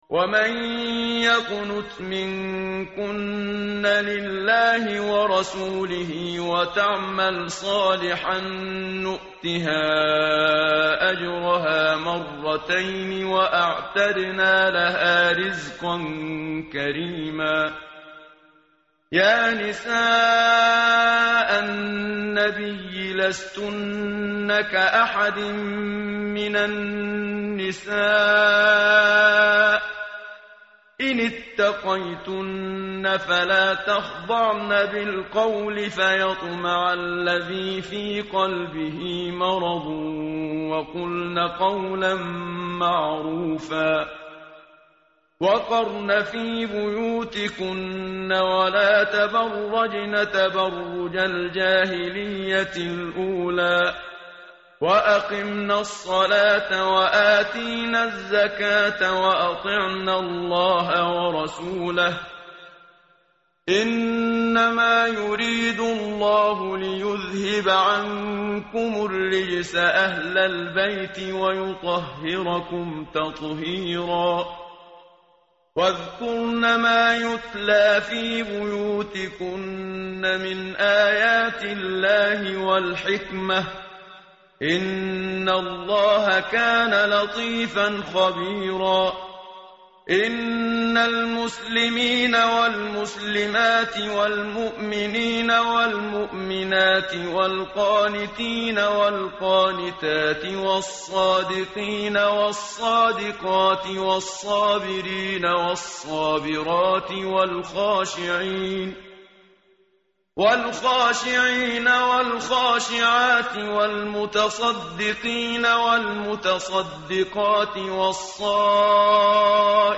متن قرآن همراه باتلاوت قرآن و ترجمه
tartil_menshavi_page_422.mp3